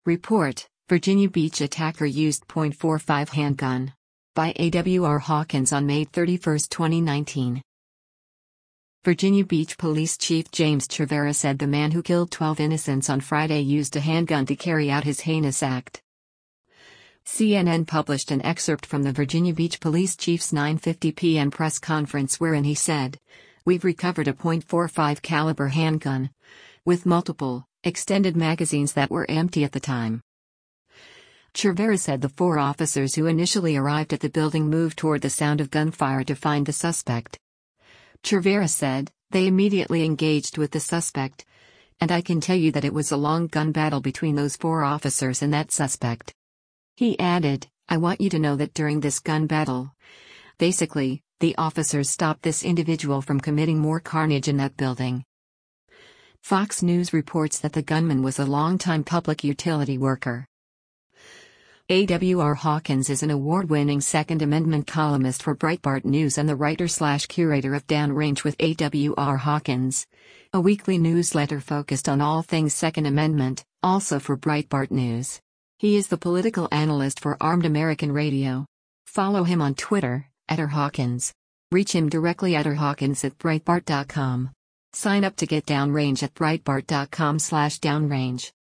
Virginia Beach Police Chief James Cervera speaks during a news conference in Virginia Beac